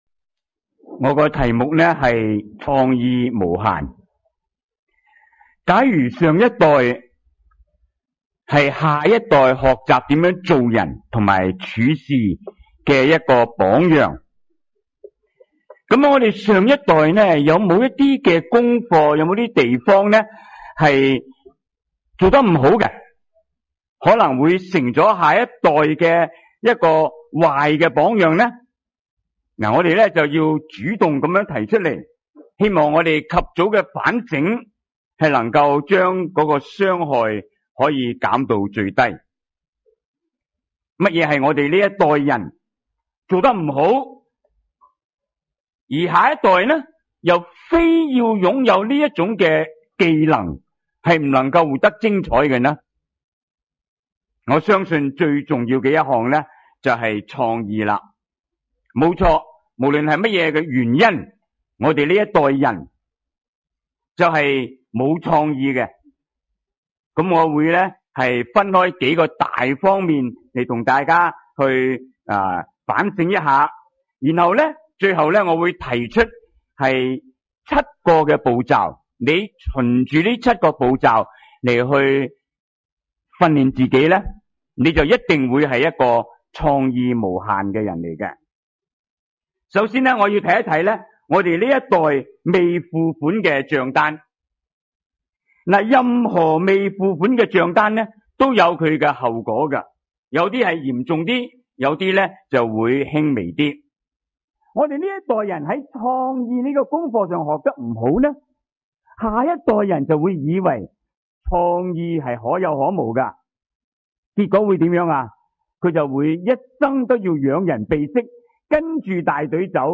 專題講座